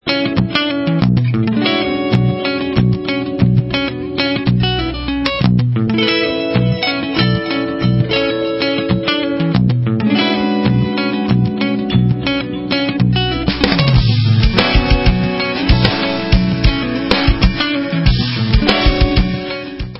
sledovat novinky v oddělení Rock/Alternative Metal